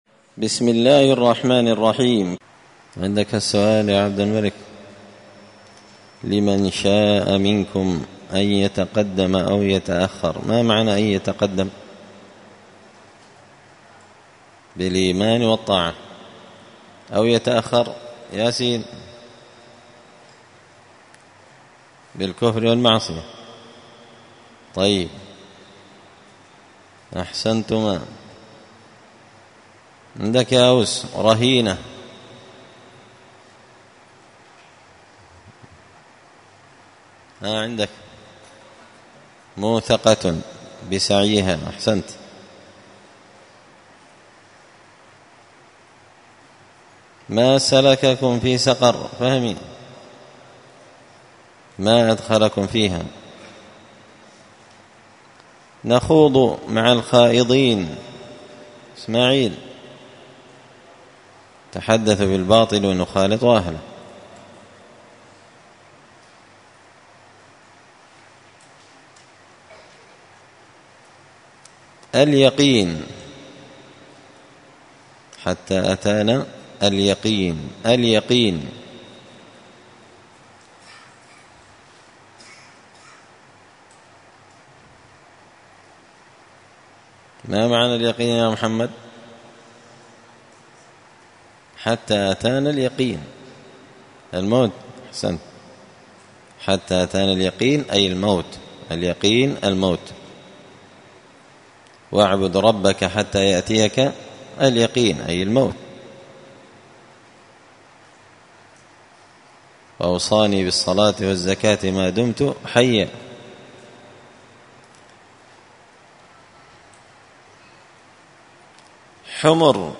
(جزء تبارك سورة المدثر الدرس 100)